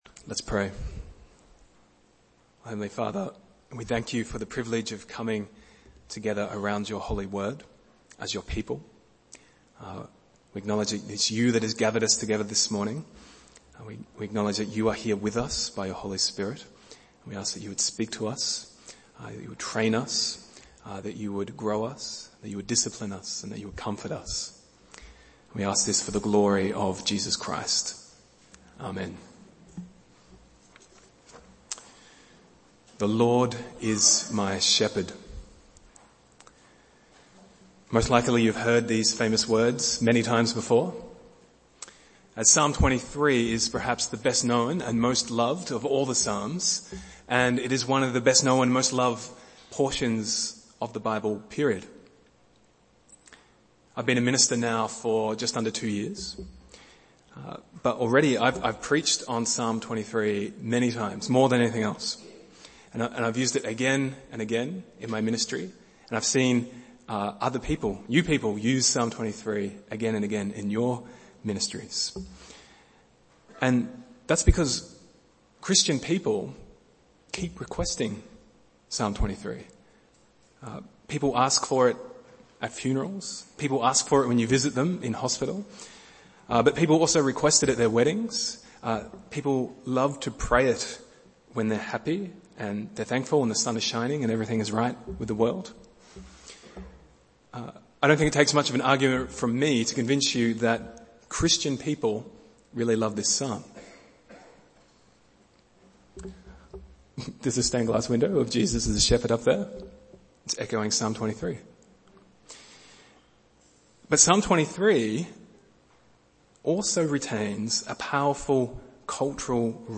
Bible Text: Psalms 23 | Preacher